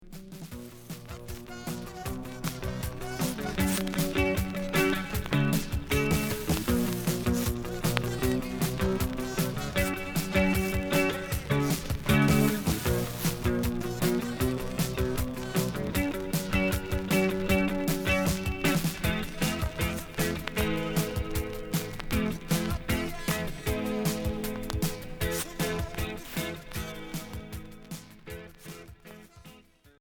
Cold rock Premier 45t retour à l'accueil